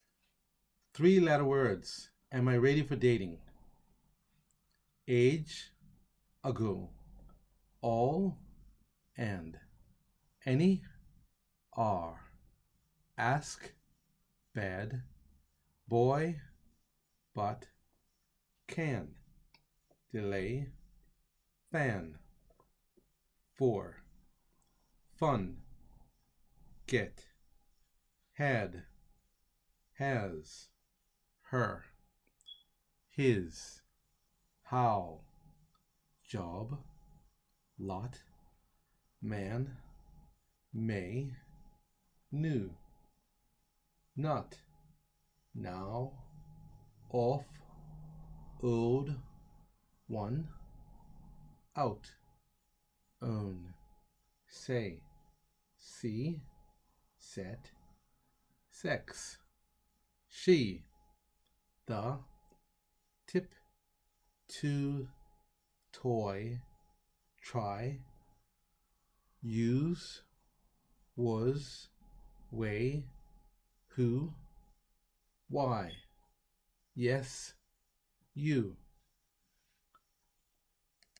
Below the vocabulary list and audio pronunciation for the presentation “Am I ready for dating and marriage” for English learners.